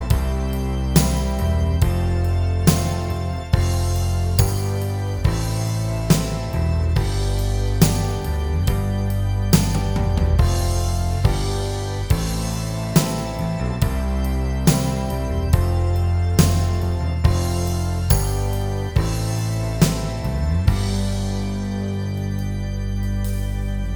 Minus All Guitars Rock 4:10 Buy £1.50